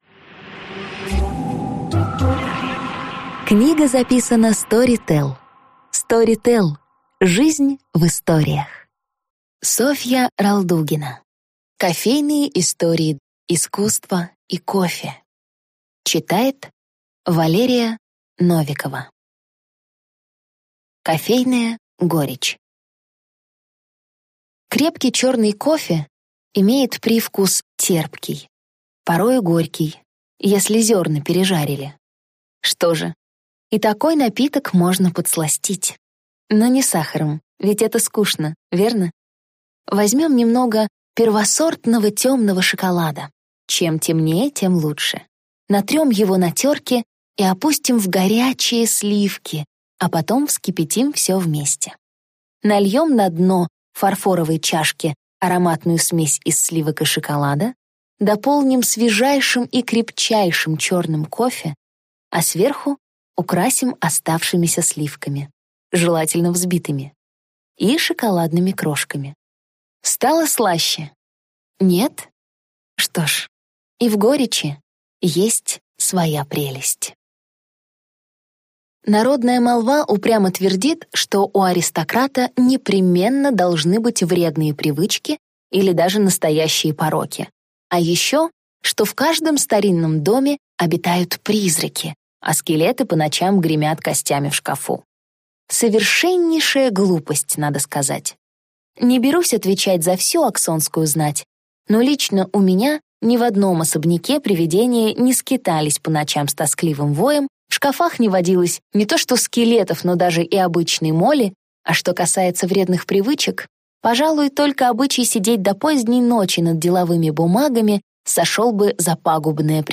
Аудиокнига Кофейная горечь | Библиотека аудиокниг